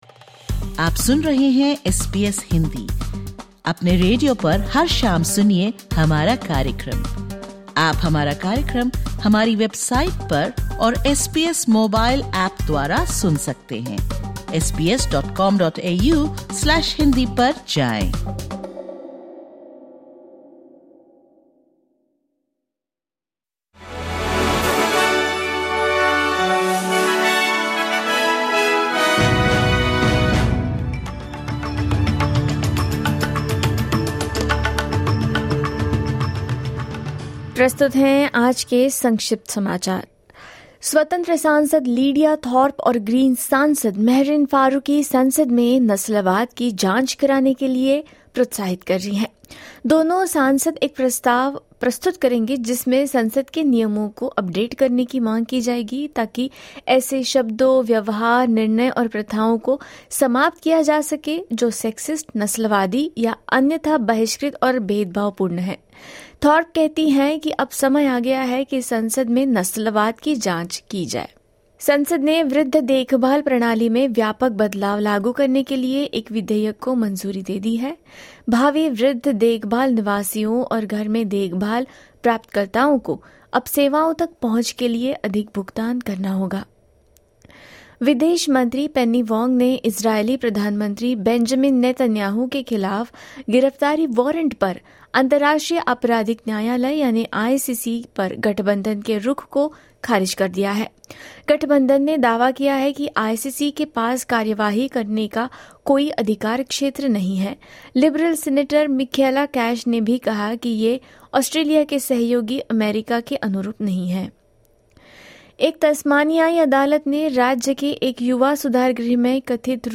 Listen to the top News from Australia in Hindi.